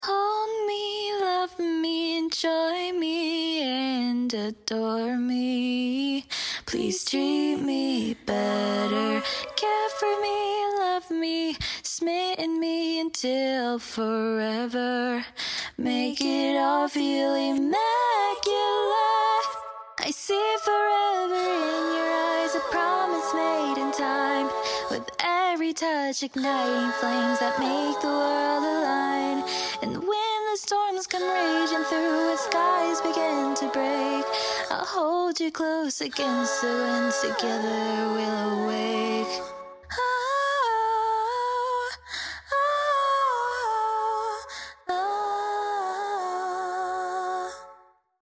so there’s my excuse for singing sloppy